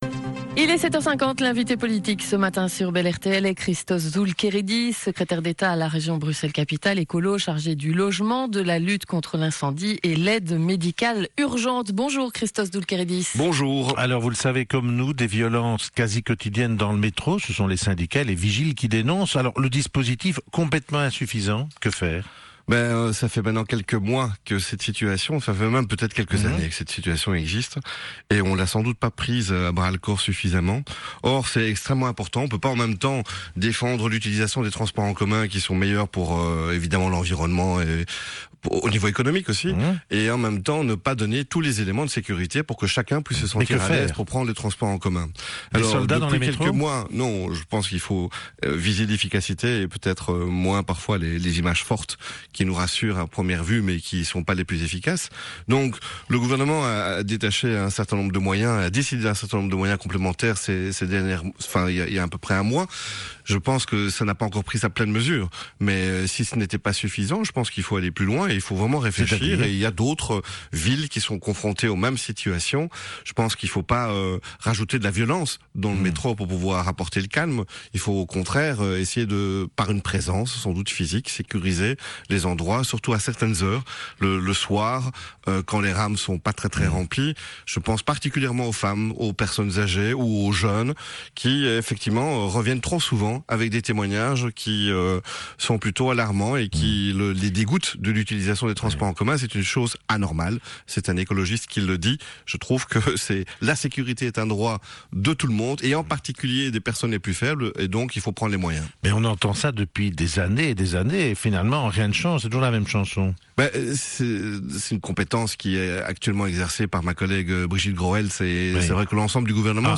Invité de Bel RTL ce matin | Christos Doulkeridis